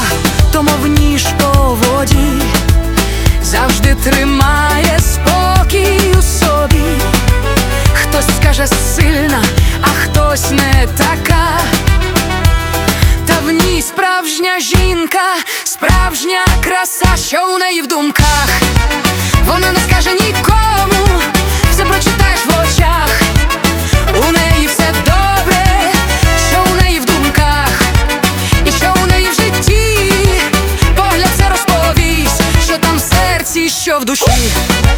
Pop
Жанр: Поп музыка / Украинские